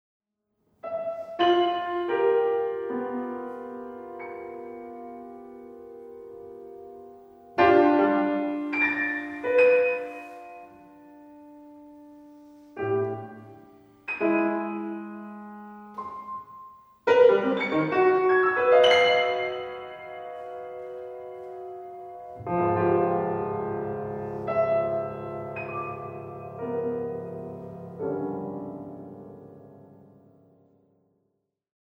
Klavier
Rec: 27. Juni 2020, Düsseldorf